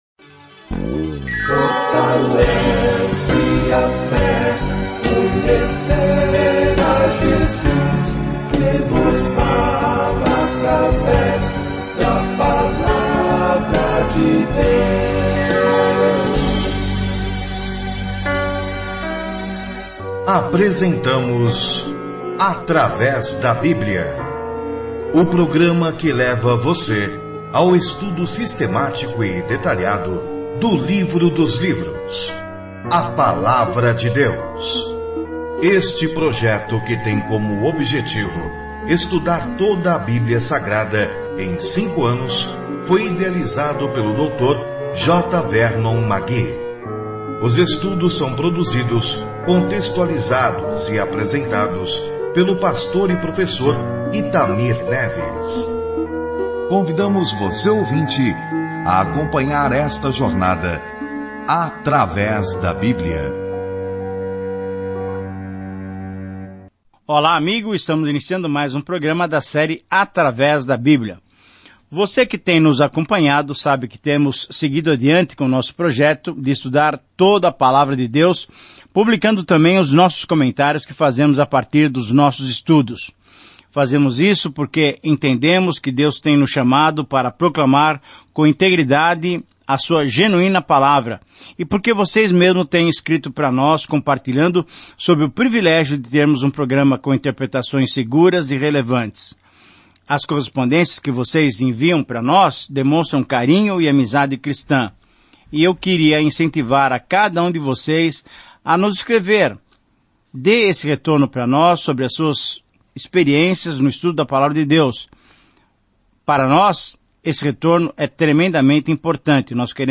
As Escrituras Eclesiastes 2:1-26 Dia 2 Começar esse Plano Dia 4 Sobre este Plano Eclesiastes é uma autobiografia dramática da vida de Salomão quando ele tentava ser feliz sem Deus. Viajando diariamente por Eclesiastes, você ouve o estudo em áudio e lê versículos selecionados da palavra de Deus.